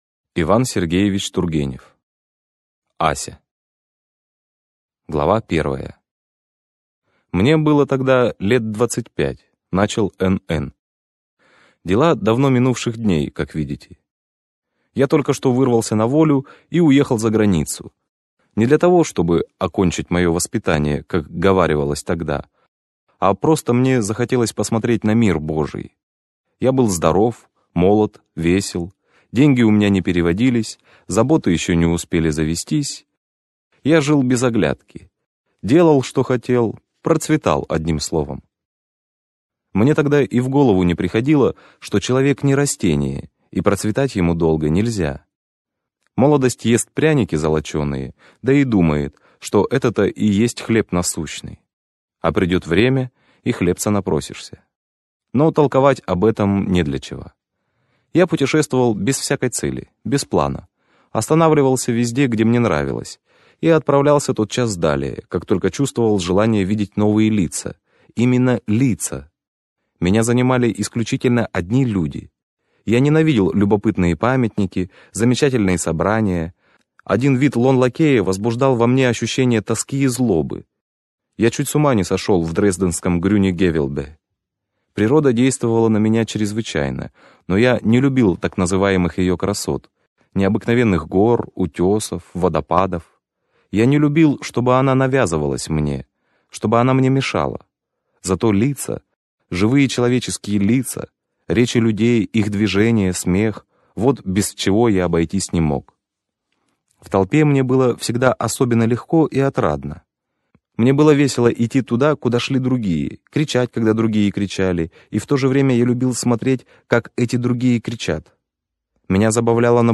Аудиокнига Ася - купить, скачать и слушать онлайн | КнигоПоиск